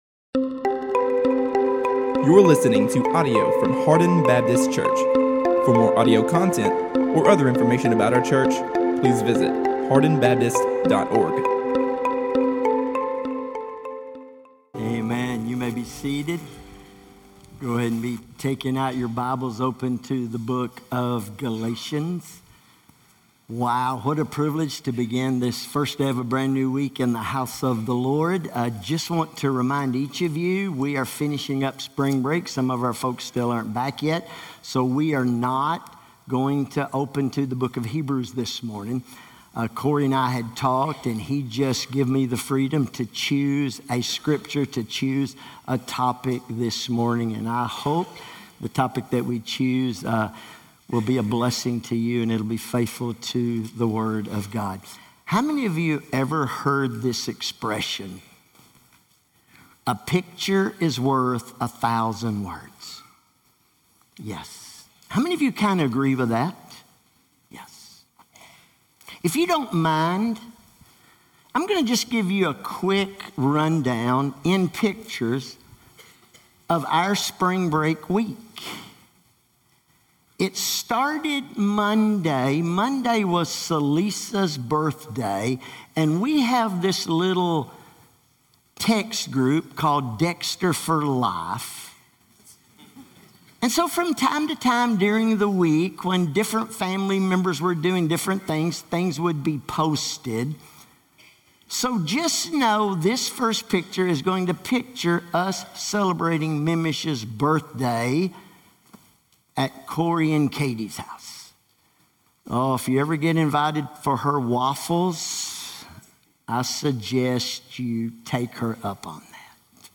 All Sermons – Hardin Baptist Church